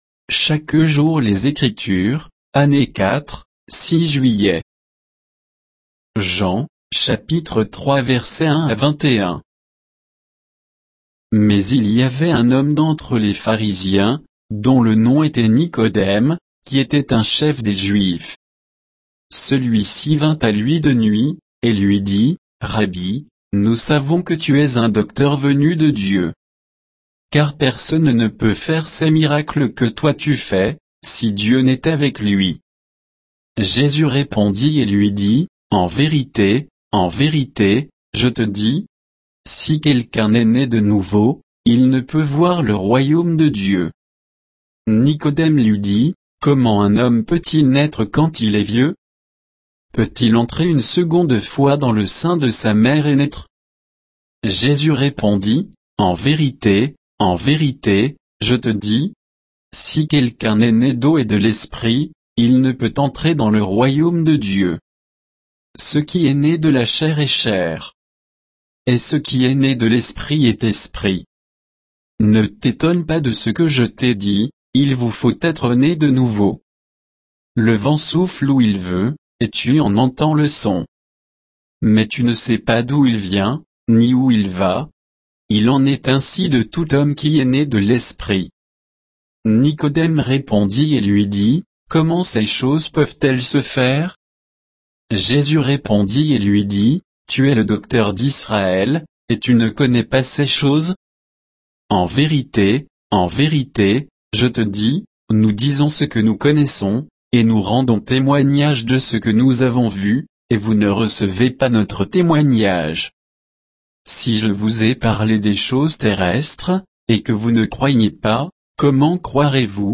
Méditation quoditienne de Chaque jour les Écritures sur Jean 3